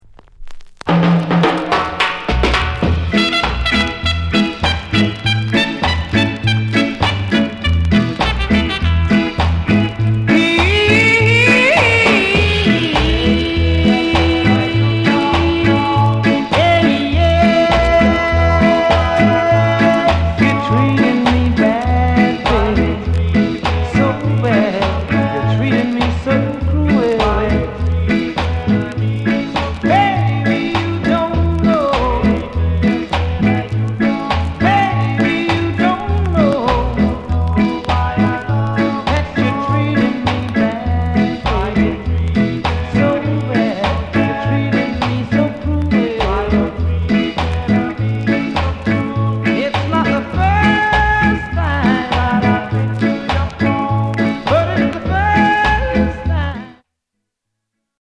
ROCKSTEADY INST